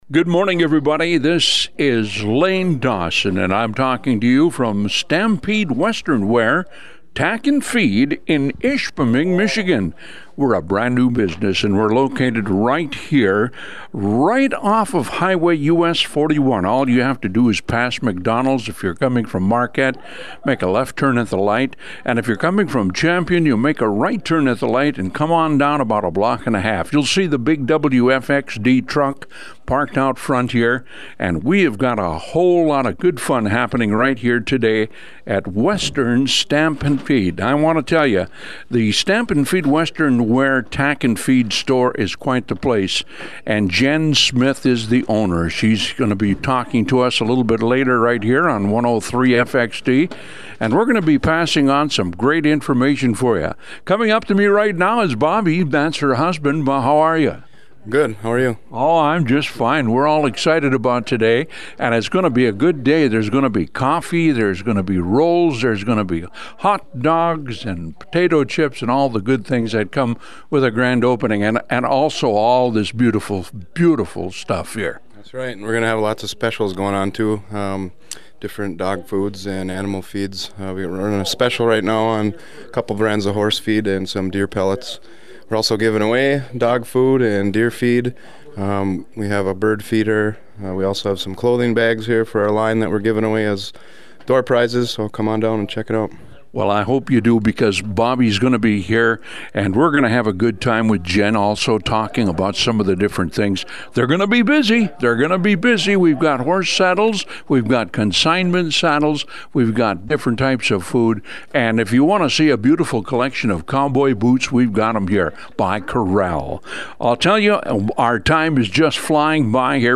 There was fun for everyone at Stampede Western Wear's Grand Opening